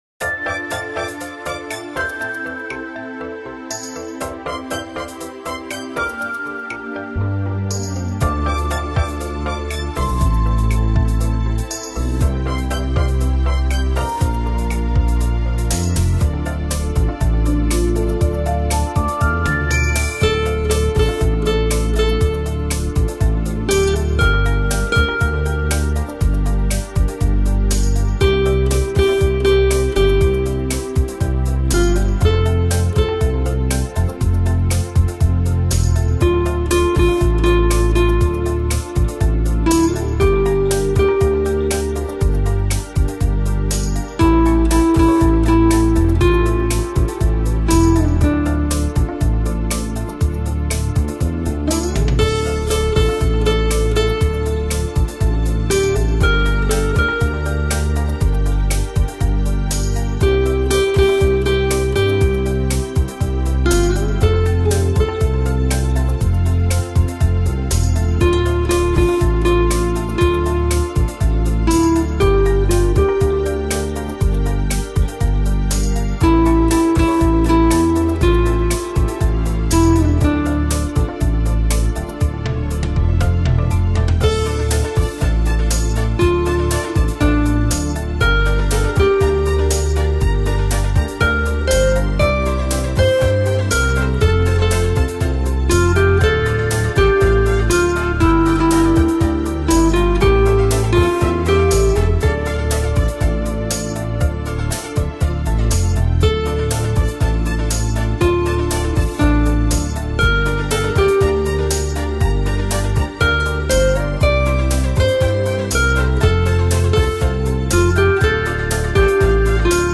Это просто дискотека!